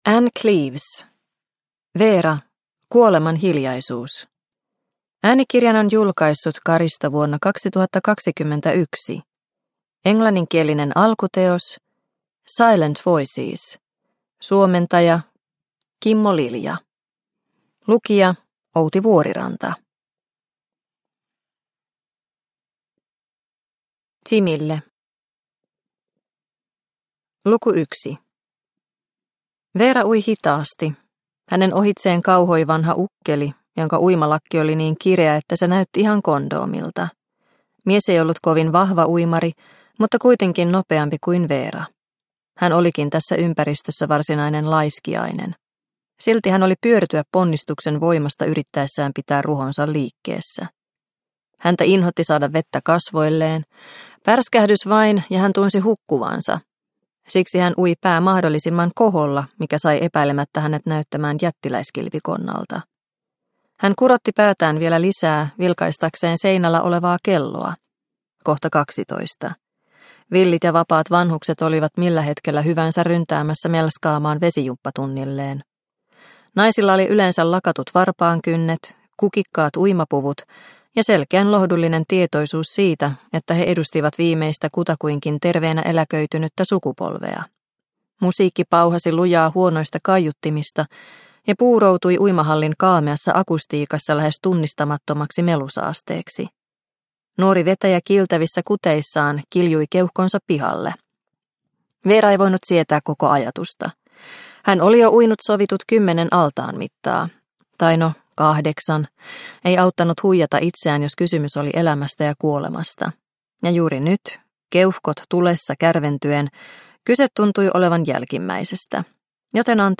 Kuolemanhiljaisuus – Ljudbok – Laddas ner